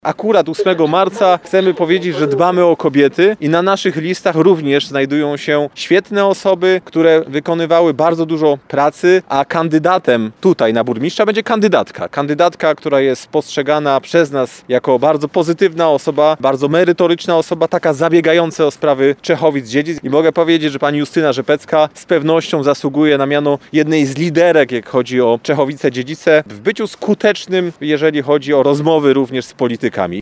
Dworzec w Czechowicach-Dziedzicach, jak chodzi o infrastrukturę oraz budynek, jest naszym oczkiem w głowie, nad którym bardzo mocno pracowaliśmy – mówił na peronie pierwszym tego dworca minister w rządzie Mateusza Morawieckiego Grzegorz Puda.